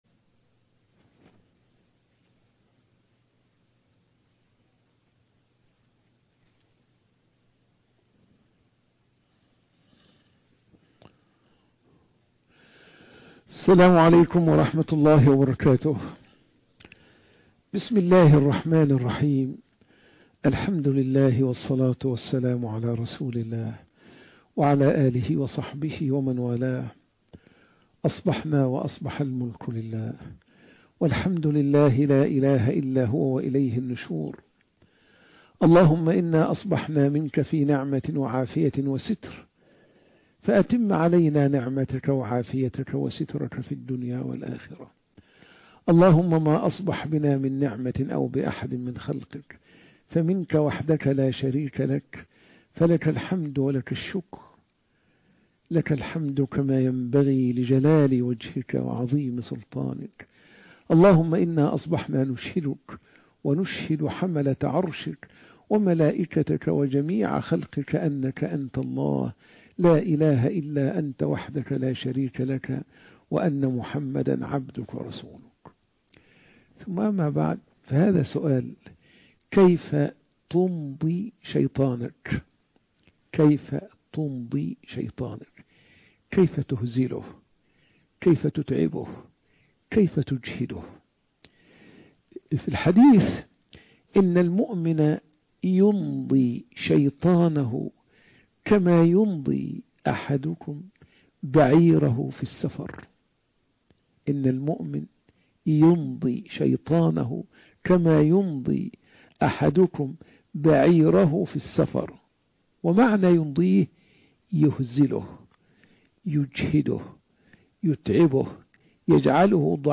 كيف تنضي شيطانك وتتعبه - درس بعد الفجر